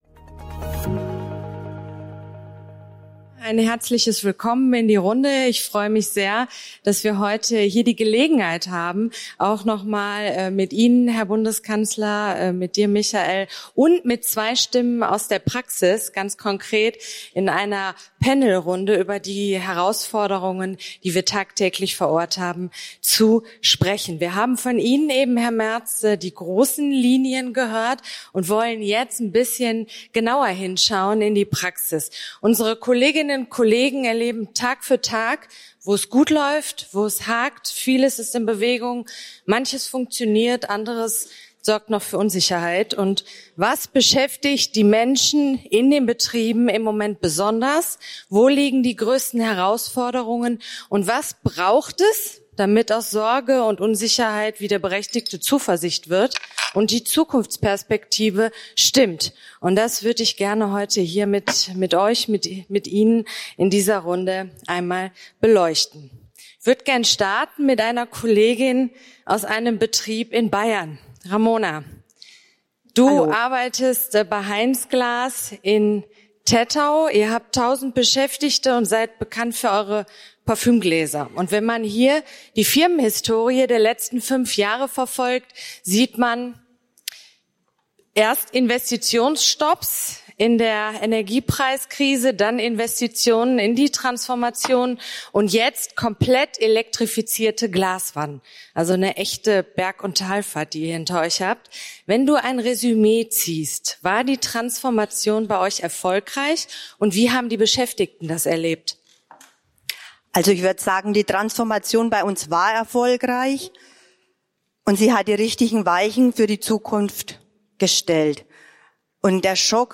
beim 8. Ordentlichen Gewerkschaftskongress.